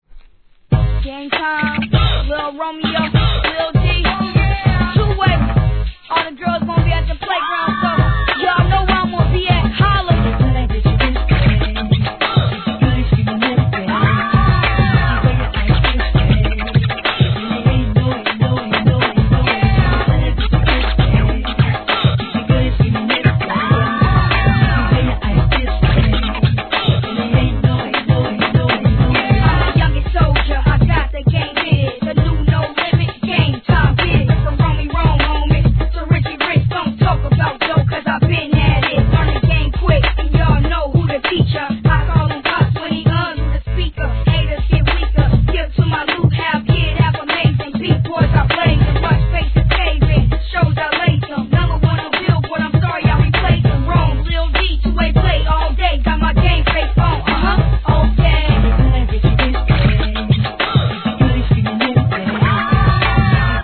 HIP HOP/R&B
大ネタ使いでは無いものの、女性の叫び声にスクラッチをはめ込んだ相変わらずテンション↑な一曲!